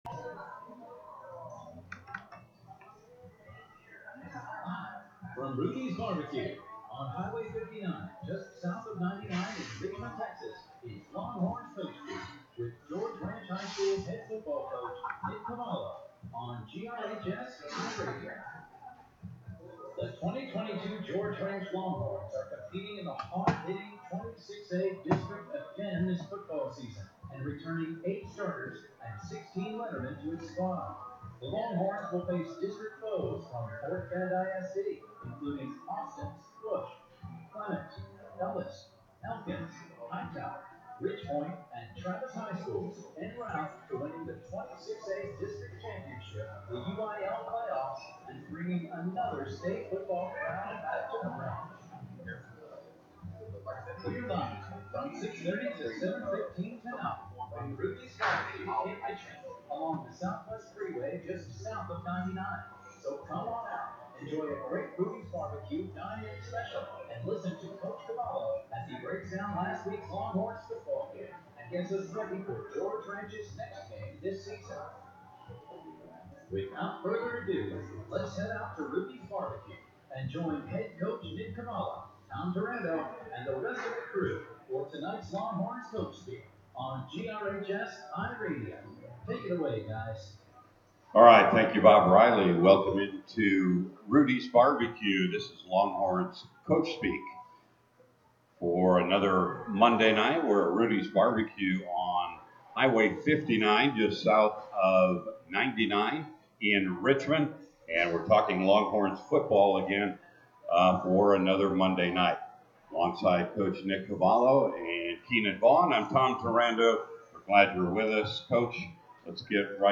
"Longhorns Coach Speak" from Rudy's Bar B Q in Richmond, Texas